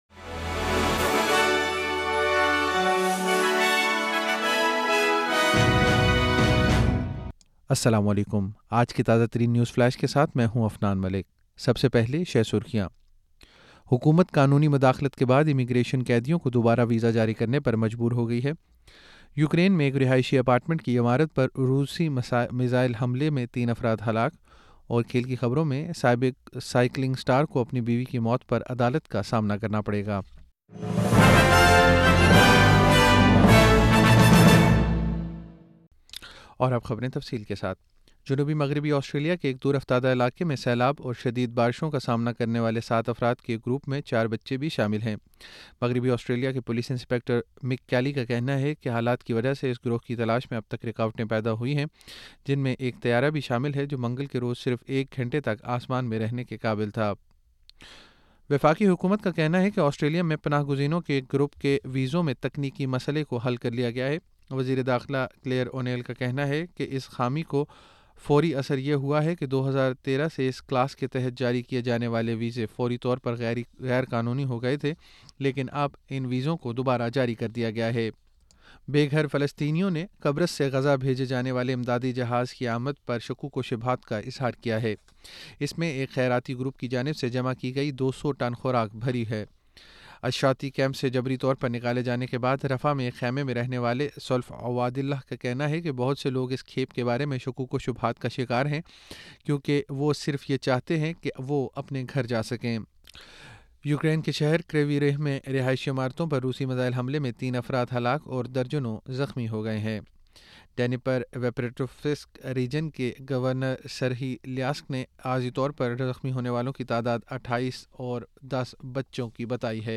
نیوز فلیش 13 مارچ 2024: حکومت قانونی مداخلت کے بعد امیگریشن قیدیوں کو دوبارہ ویزا جاری کرنے پر مجبور